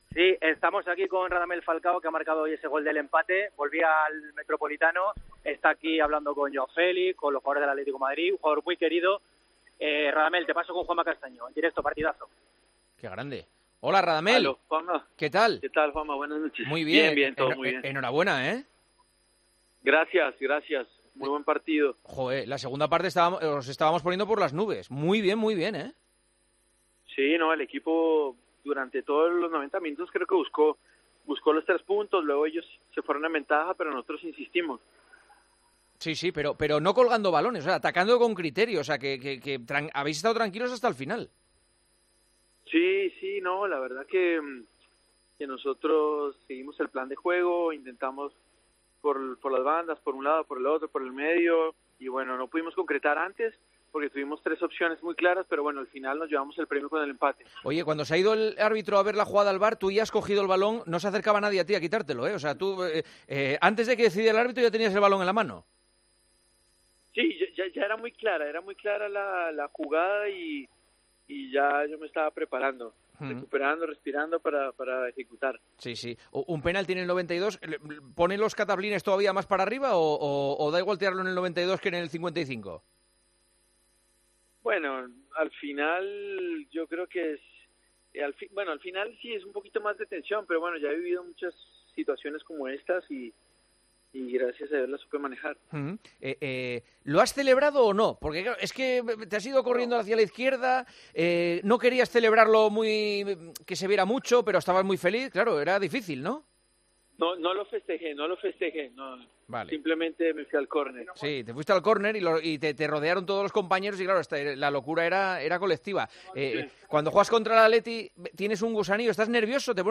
Juanma Castaño entrevistó en El Partidazo de COPE a Radamel Falcao, delantero del Rayo Vallecano que logró el empate en el descuento en el Estadio Metropolitano ante el Atlético de Madrid: "El equipo buscó los tres puntos durante los noventa minutos. Tuvimos tres opciones muy claras pero nos llevamos el premio con el empate".